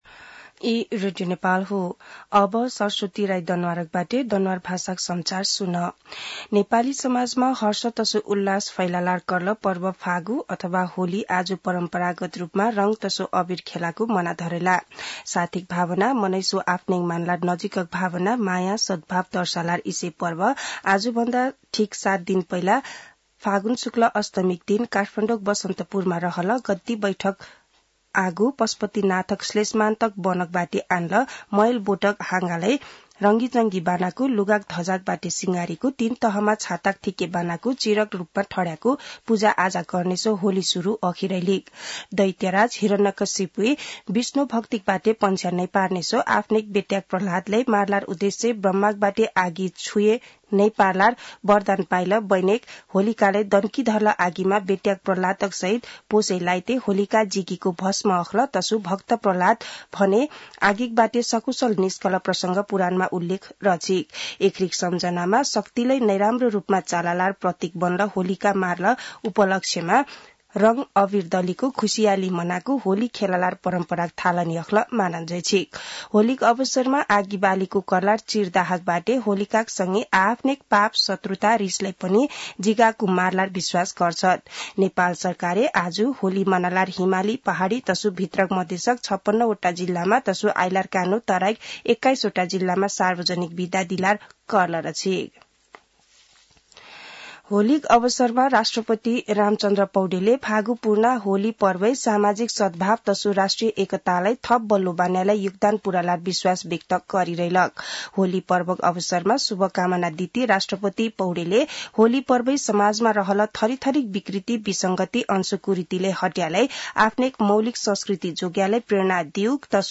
दनुवार भाषामा समाचार : ३० फागुन , २०८१
Danuwar-News-1.mp3